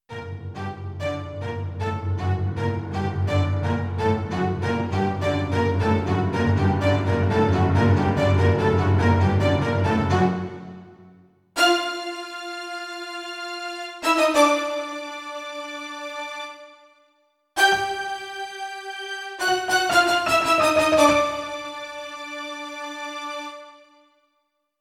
Orchestral & Instrumental Composer